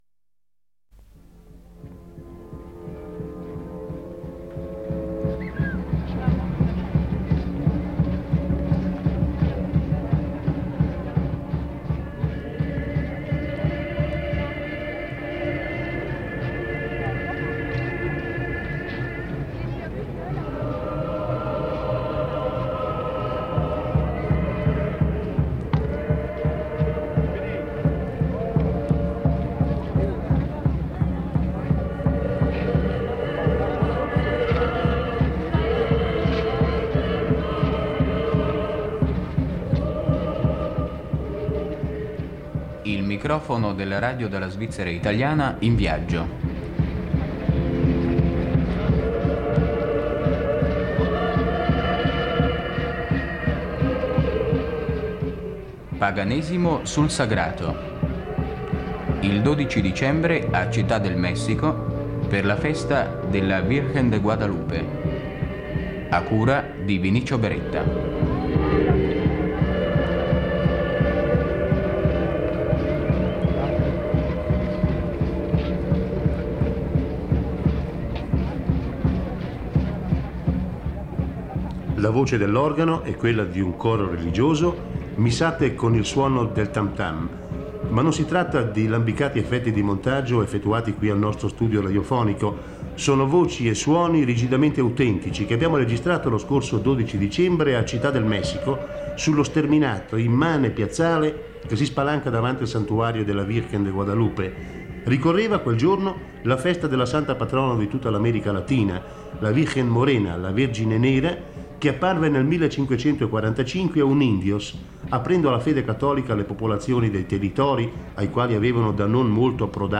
Le celebrazioni trasformano la città in un caleidoscopio di devozione e folklore, dove il sacro si intreccia al profano e il cattolicesimo si fonde con antiche tradizioni indigene. Canti, danze, processioni e rituali raccontano l’anima sincretica del Messico.